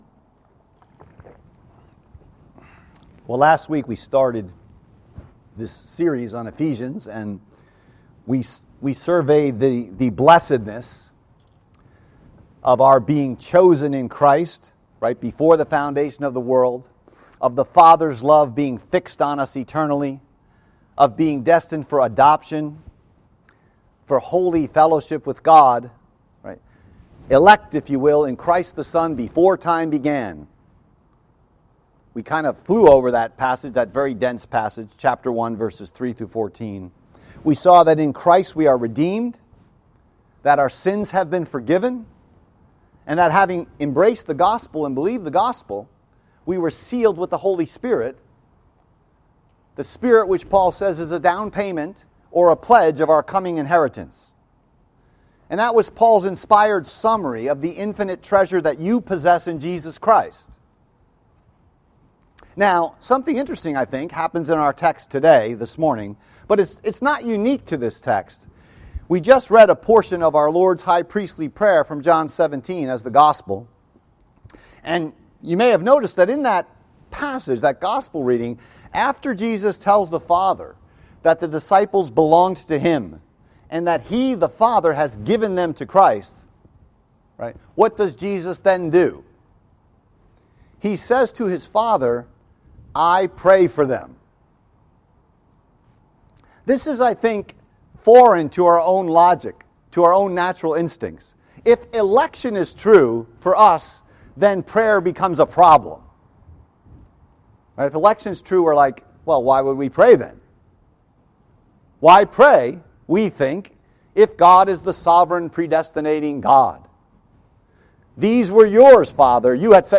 Message Text: Ephesians 1:15-23